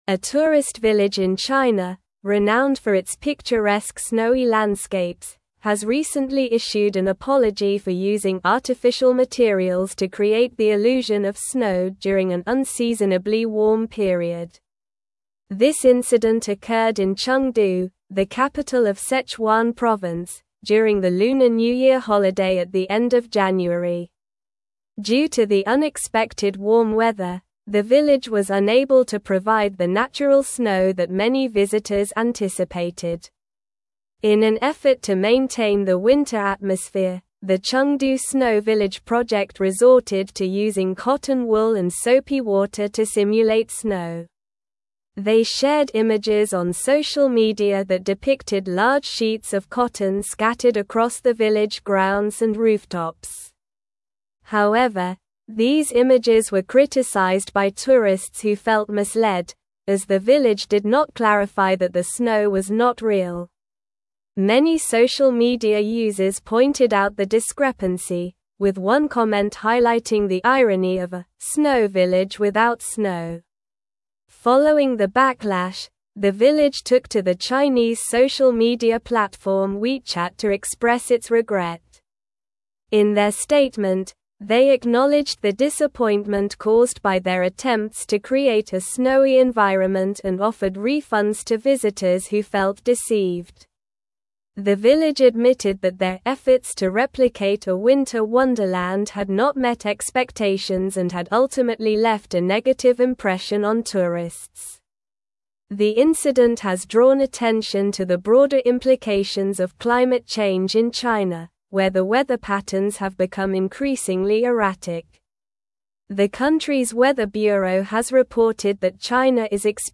Slow
English-Newsroom-Advanced-SLOW-Reading-Chengdu-Village-Apologizes-for-Fake-Snow-Misleading-Tourists.mp3